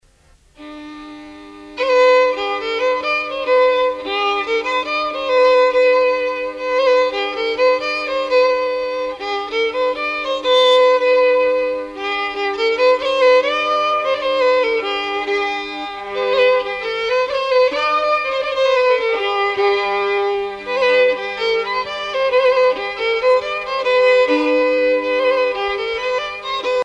Suite de gavotte
danse : gavotte bretonne
Pièce musicale éditée